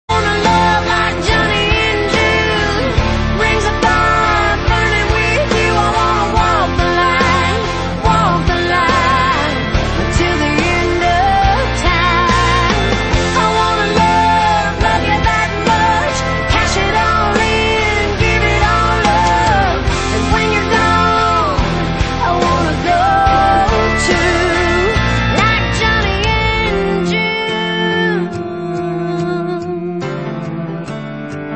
• Country Ringtones